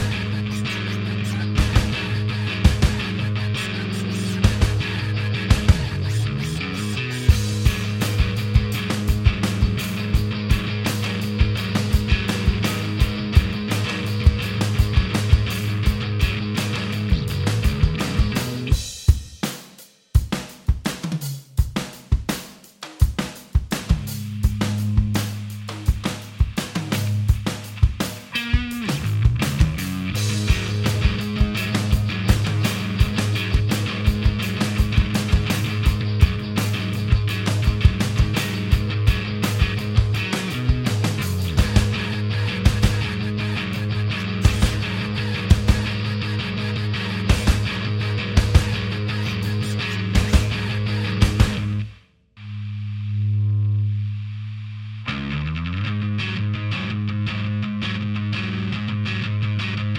Minus Main Guitar For Guitarists 3:00 Buy £1.50